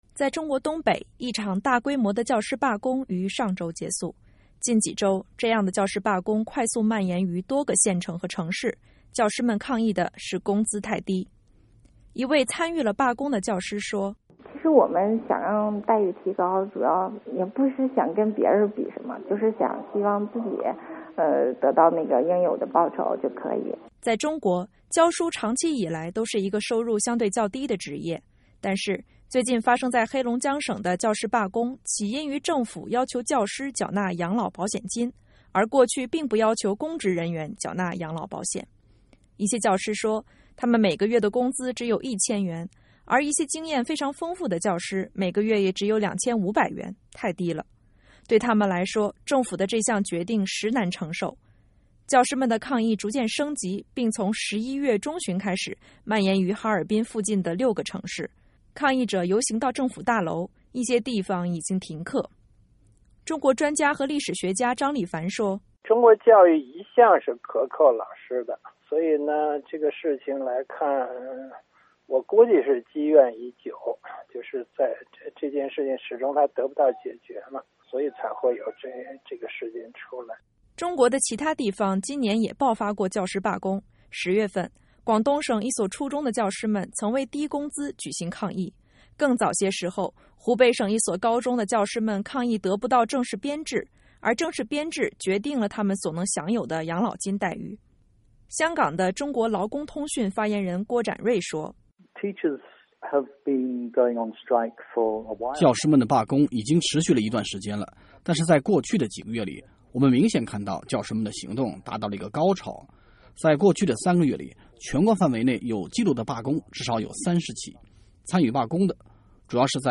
VOA快讯
一位参与了罢工的女教师说：“其实我们想让待遇提高，也不是想跟别人比什么，只是希望自己得到应有的报酬就可以。”